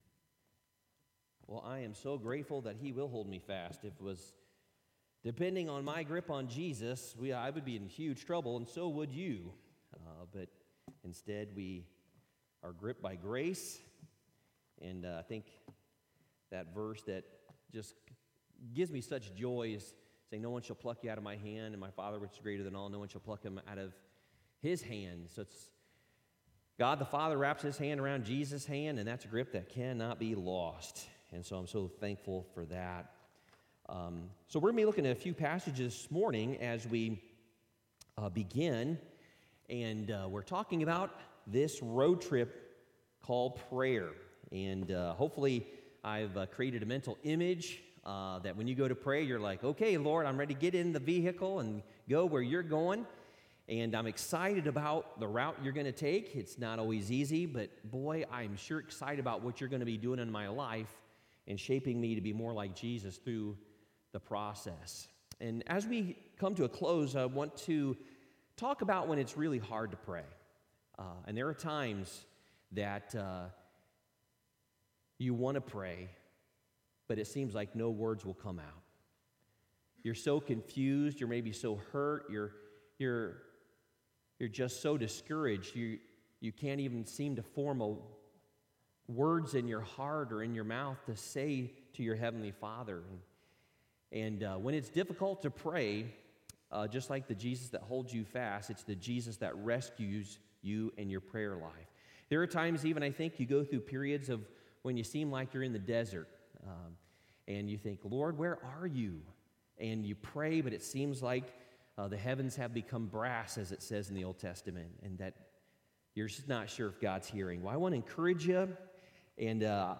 Worship Service 05/23/2021